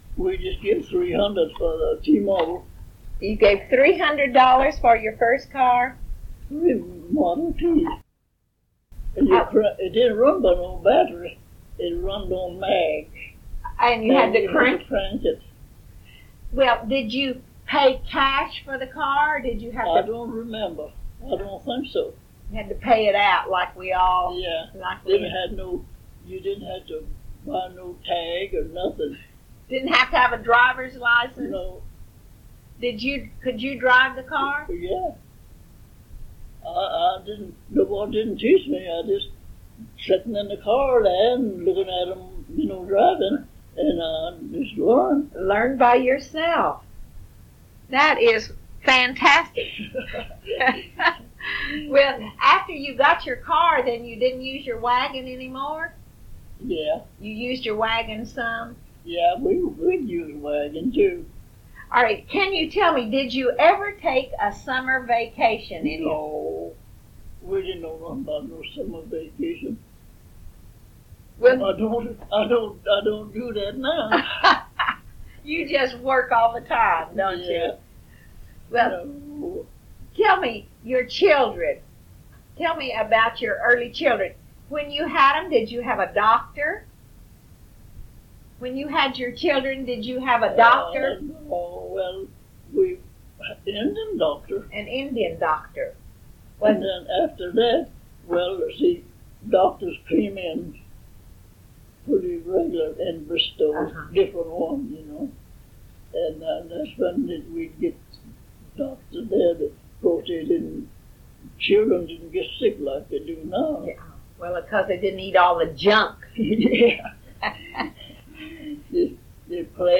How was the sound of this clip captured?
Oral History Archive